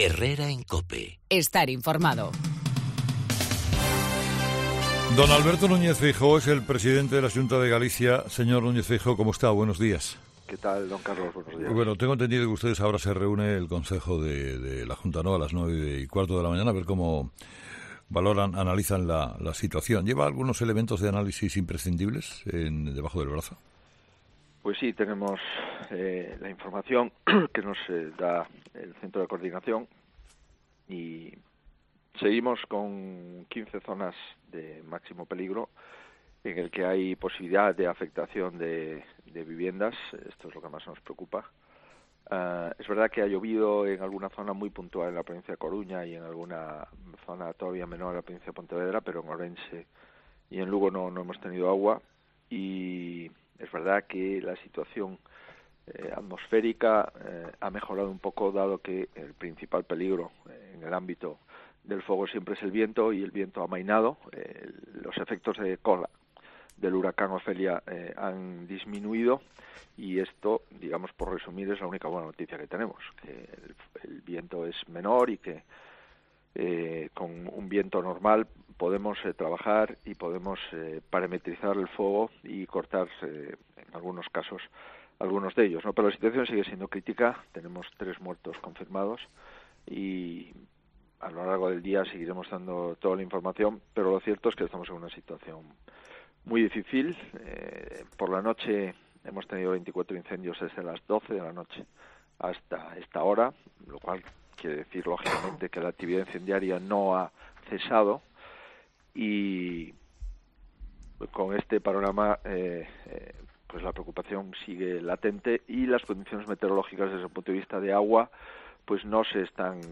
Escucha al presidente de la Xunta, Alberto Núñez Feijóo